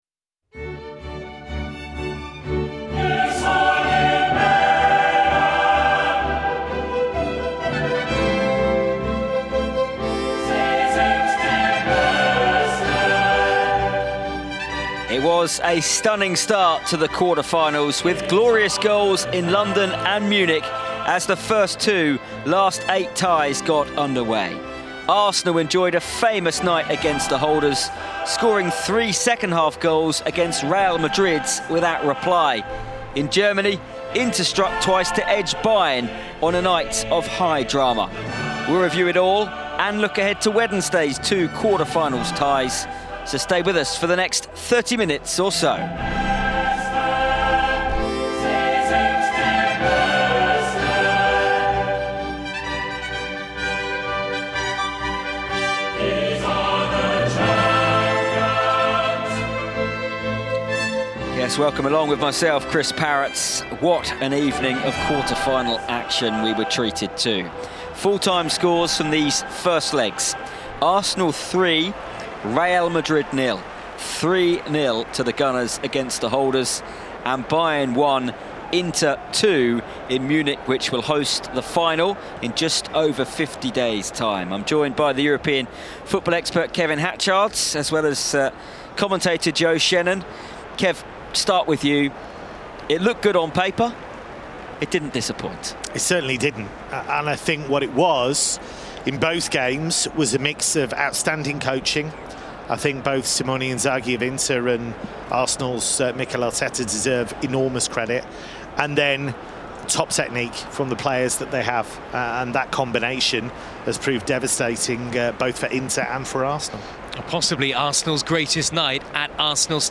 In the podcast, we hear from Arsenal's goal-scorers Rice & Merino, plus manager Mikel Arteta, as well as Madrid’s Jude Bellingham. Plus we look ahead to Wednesday's two quarter-finals first legs and hear from Villa manager Unai Emery.